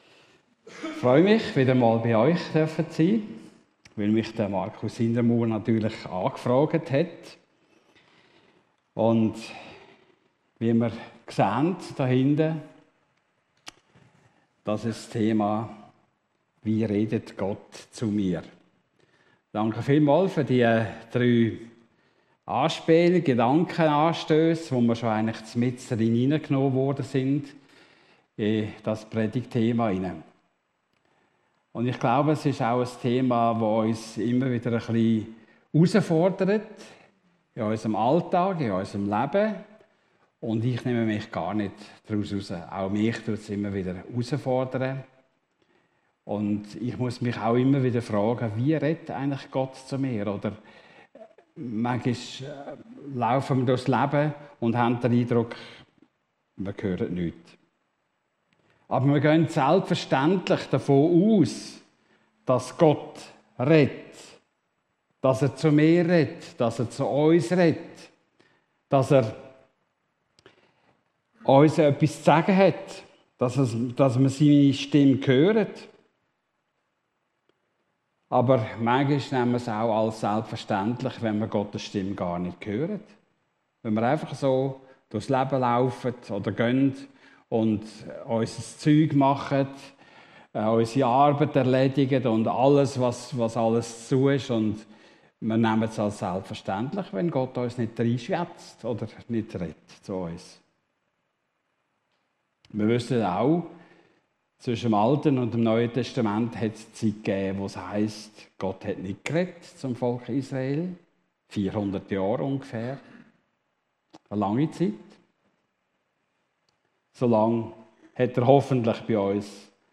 Erweiterte Suche Wie redet Gott zu uns? vor 9 Monaten 28 Minuten 0 0 0 0 0 0 Podcast Podcaster Predigten D13 Hier hörst du die Predigten aus unserer Gemeinde.